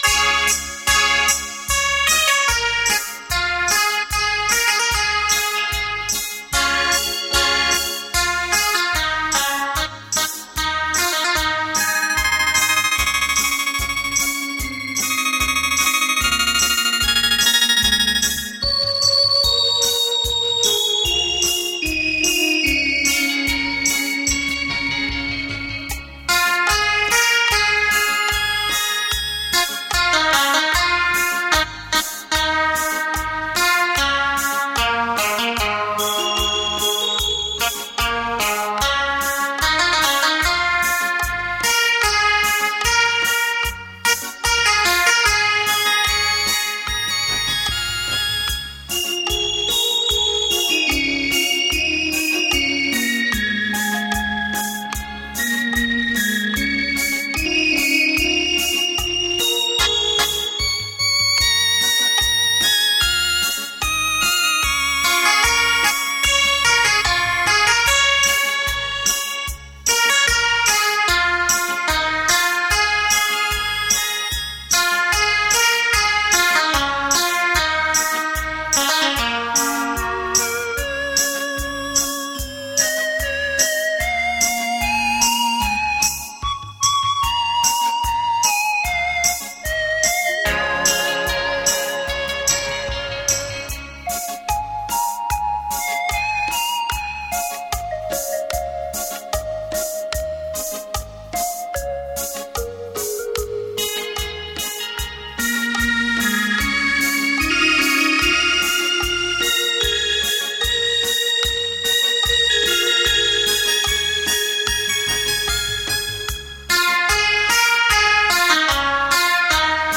优美的旋律 清脆的音感